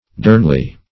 dernly - definition of dernly - synonyms, pronunciation, spelling from Free Dictionary
dernly - definition of dernly - synonyms, pronunciation, spelling from Free Dictionary Search Result for " dernly" : The Collaborative International Dictionary of English v.0.48: Dernly \Dern"ly\, adv. Secretly; grievously; mournfully.